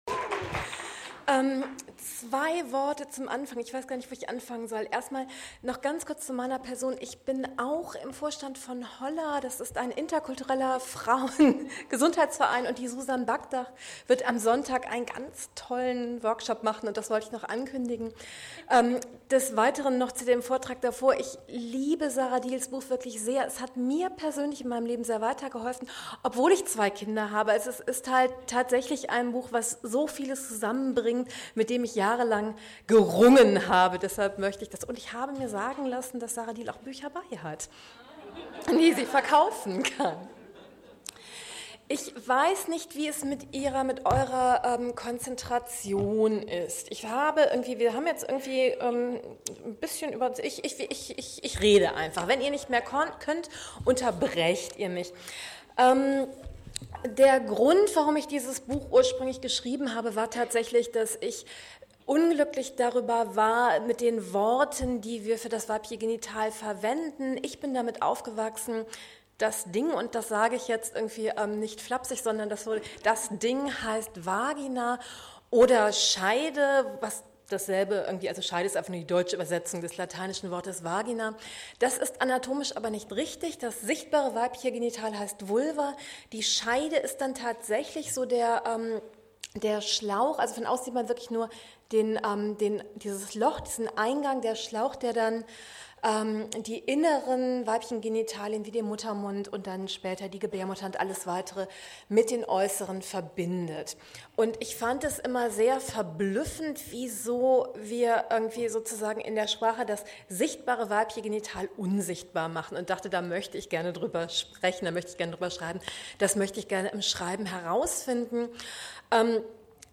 Vortrag von Dr. Mithu M. Sanyal
Vortrag von Dr. Mithu M. Sanyal auf dem LACHESIS Kongress 2017 Dr Mithu M Sanyal.mp3 MP3 Audio Datei [27.9 MB]